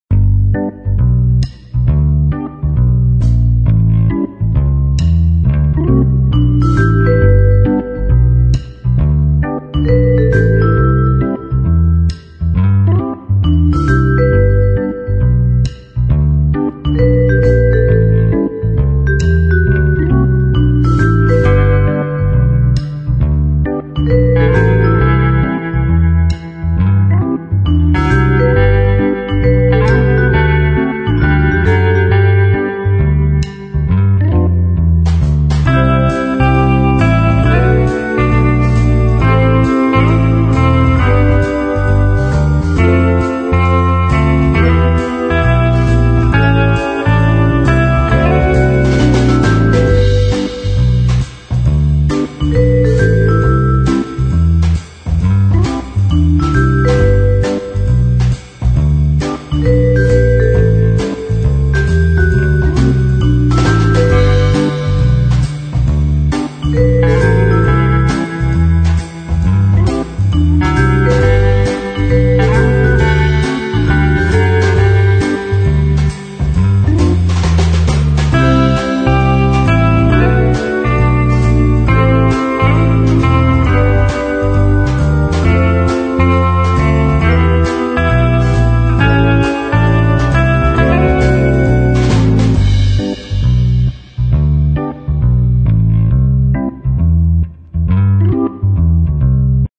描述：快乐，顺利和放松休闲爵士与复古的感觉。 乐器包括电颤琴、电风琴、鼓和电吉他。